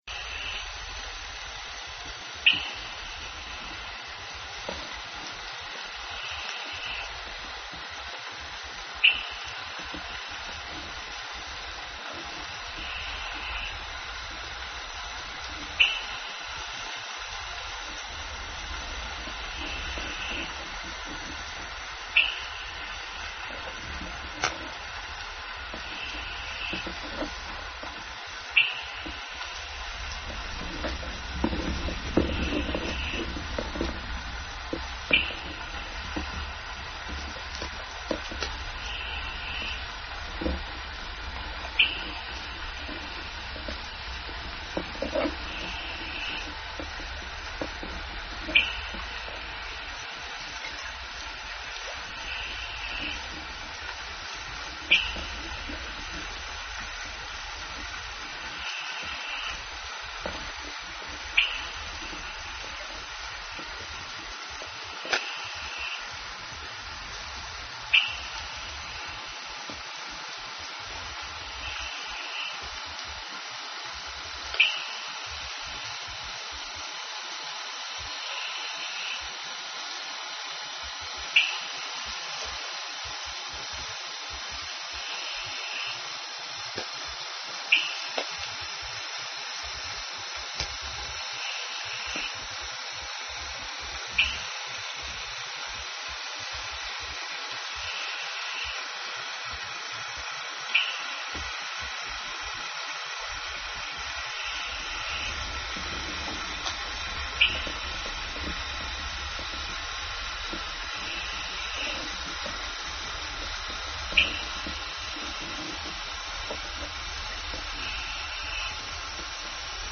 8 minute recordings from Lime Kiln and Orcasound
Lime Kiln 8-minute recording (10:41)
Spectrogram of 8-minute recording from the Lime Kiln hydrophone.